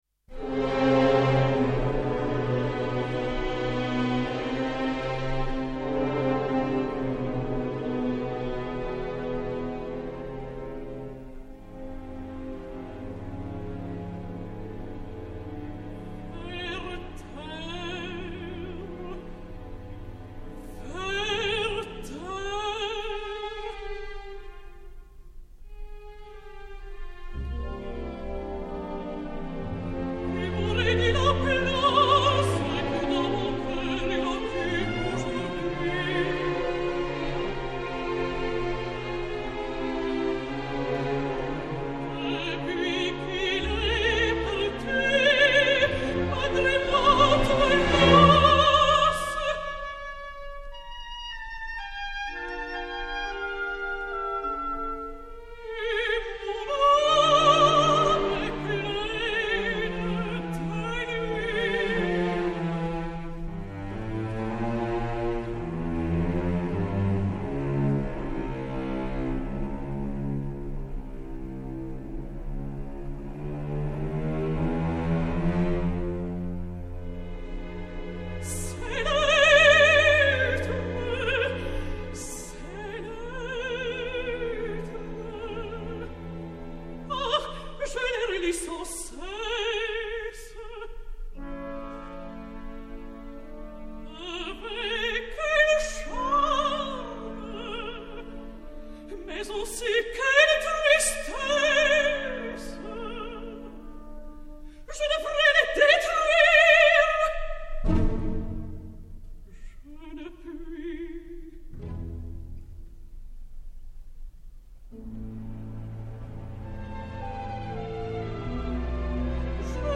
mezzo-soprano belge